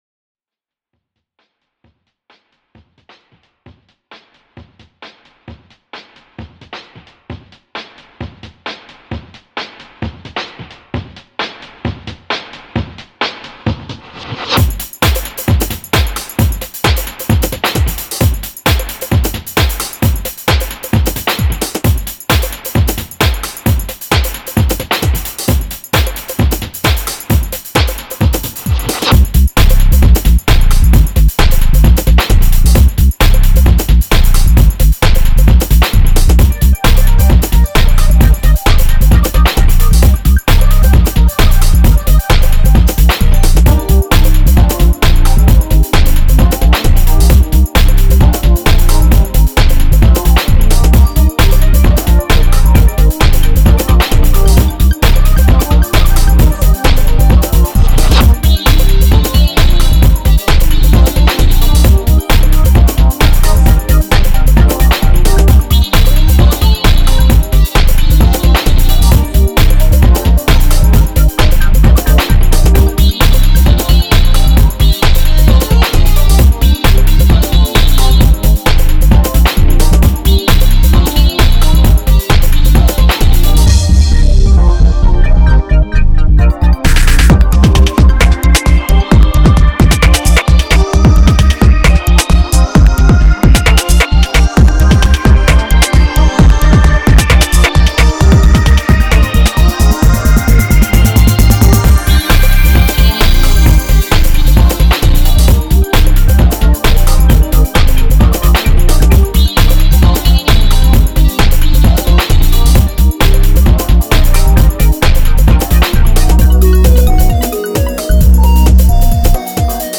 Dance Track
Not necessarily built to loop, but could be edited for it.
dancetrack.mp3